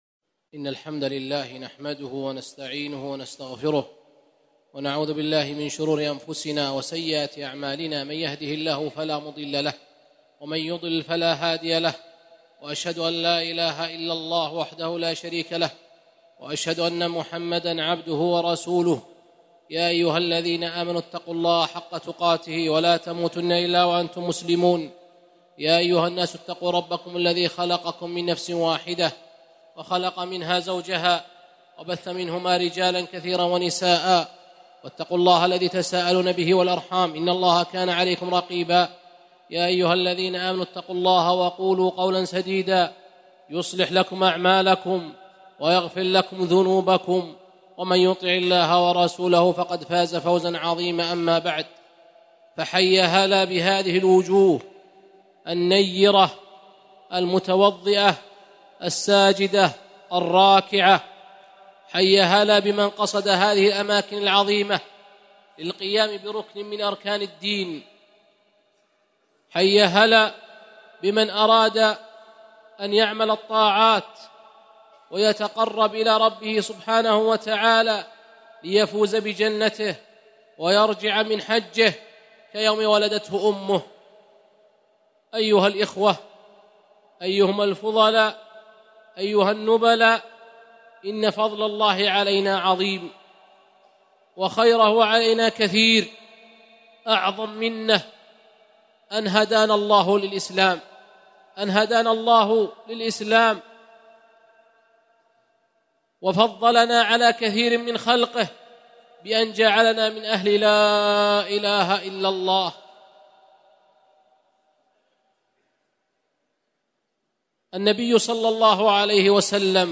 محاضرة - التحذير من الغلو والتطرف